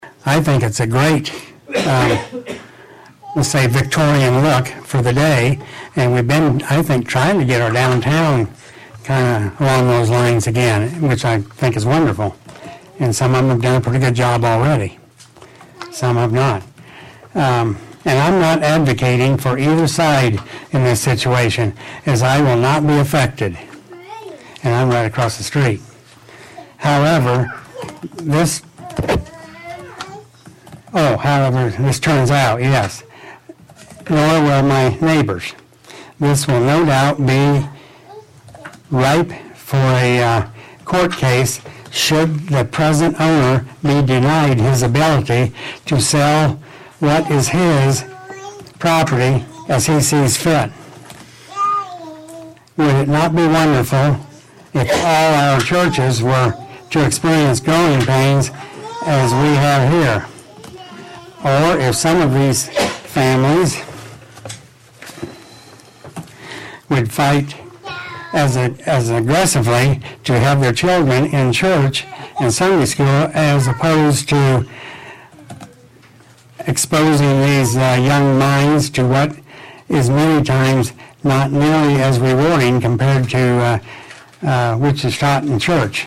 The Board consisting of Chairperson Melissa Ihnen, Vice-Chair, Alexsis Fleener, Brian Ruge, and Lloyd Munson, held a public hearing on Thursday night in front of a large crowd in the City Council Chambers.